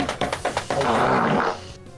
PLAY Husky espasmos
husky.mp3